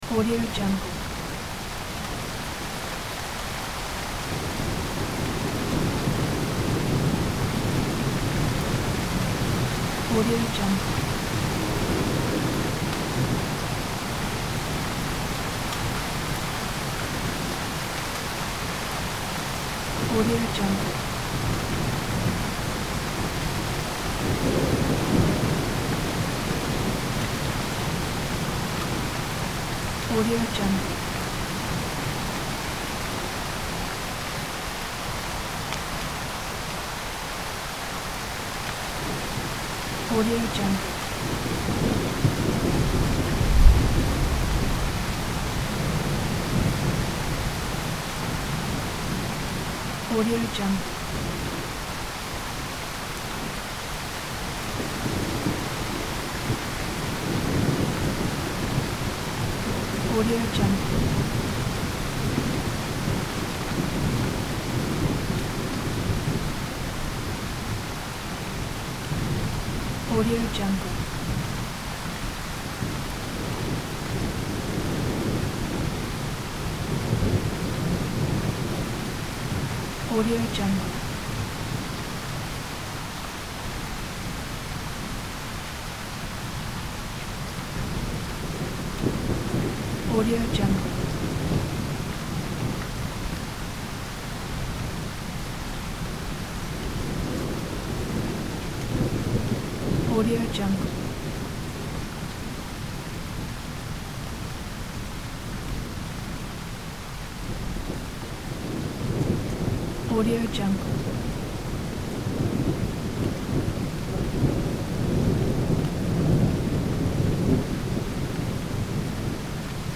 دانلود افکت صدای رعد و برق و باران سیل آسا
این فایل با کیفیت استودیویی، صدای واقعی رعد و برق و باران شدید را به طور طبیعی شبیه‌سازی کرده است.
• کیفیت استودیویی: صدای ضبط شده در محیط استودیو و با استفاده از تجهیزات حرفه‌ای، کیفیت بسیار بالایی دارد و به پروژه‌های شما حرفه‌ای‌تر می‌بخشد.
• تنوع صدا: این فایل شامل انواع مختلف صداهای رعد و برق و باران است که به شما امکان می‌دهد تا بهترین صدا را برای پروژه خود انتخاب کنید.
• بیت‌ریت بالا: صدای واضح و بدون نویز به لطف بیت‌ریت بالا.
16-Bit Stereo, 44.1 kHz